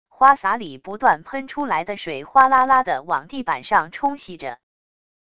花洒里不断喷出来的水哗啦啦地往地板上冲洗着wav音频